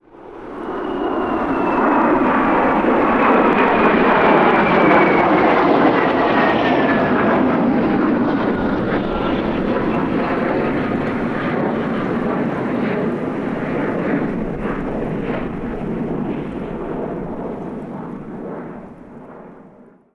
Avión F18 pasando 2
avión
Sonidos: Transportes